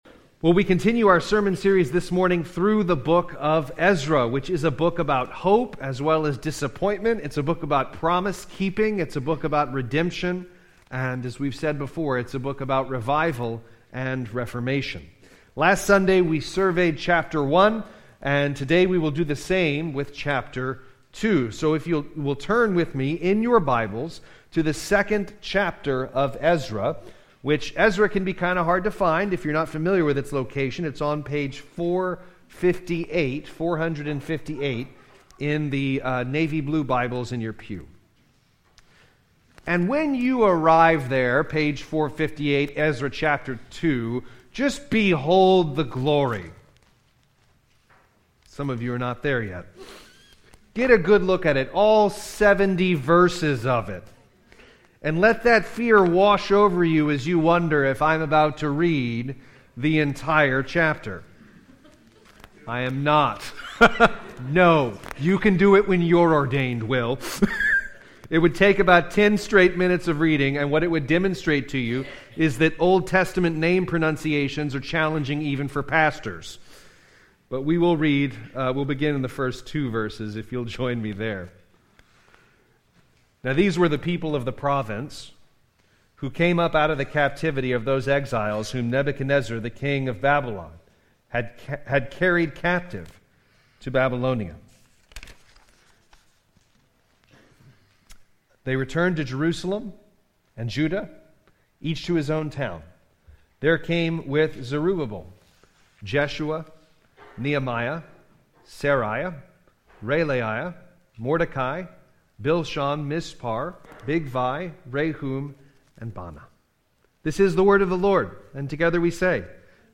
Sermons by Grace Presbyterian Church - Alexandria, LA